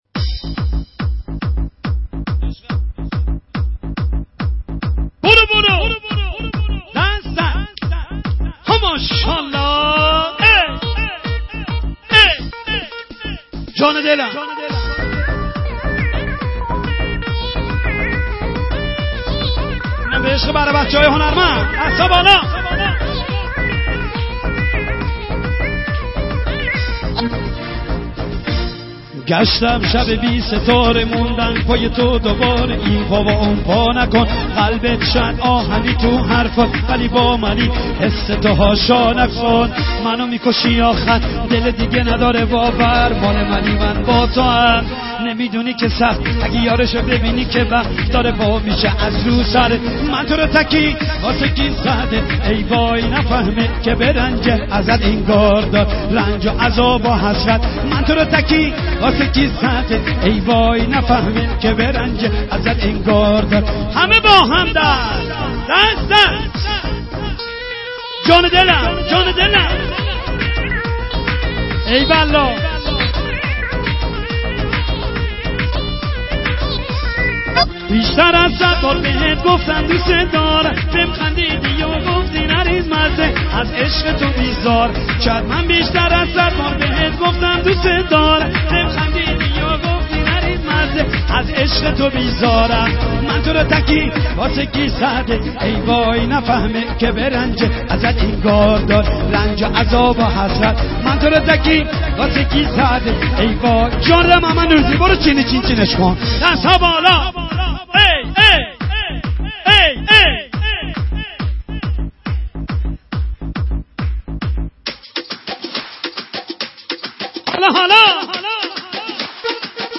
آهنگ فارسی